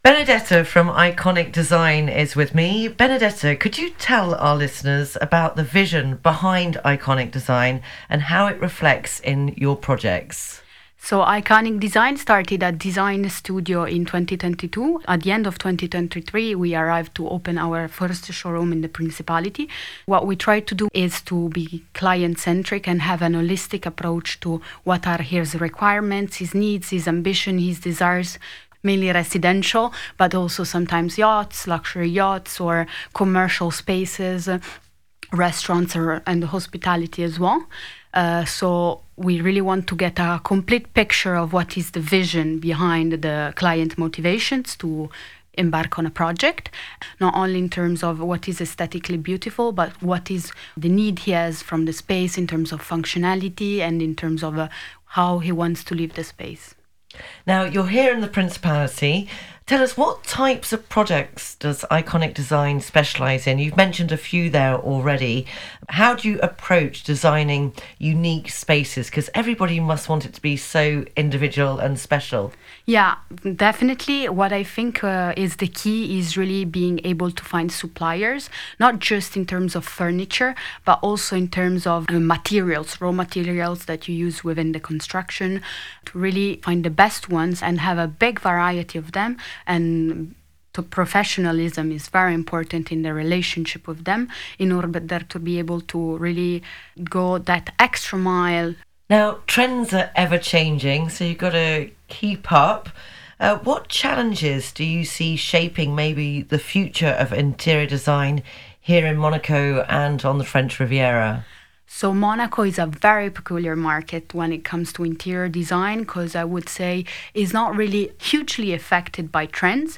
Ecoutez notre interview sur Riviera Radio.